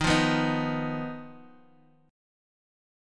Ebsus2#5 chord